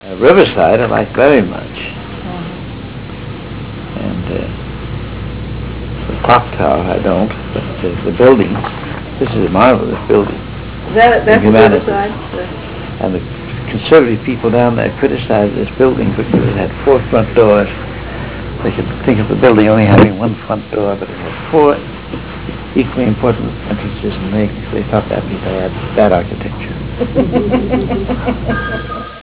234Kb Ulaw Soundfile Hear Ansel Adams discuss this photo: [234Kb Ulaw Soundfile]